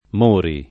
[ m 1 ri ]